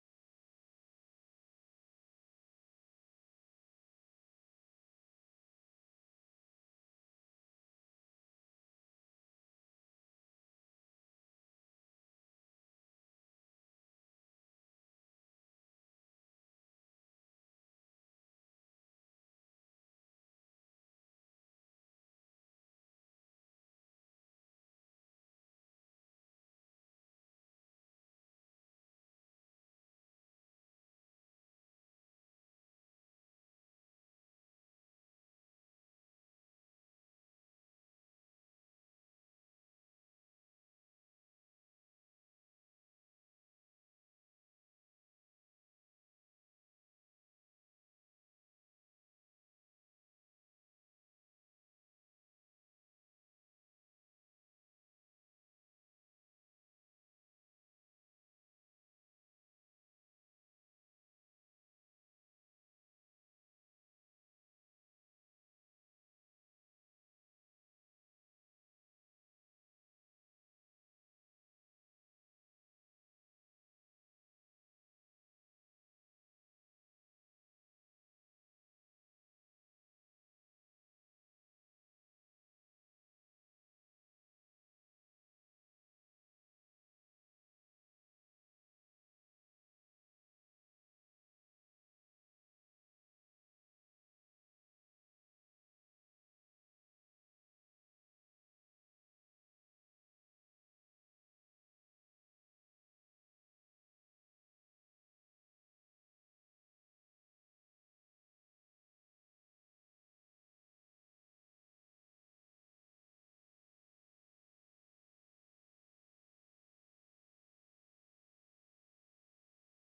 FWC 16th of February 2025 Praise and Worship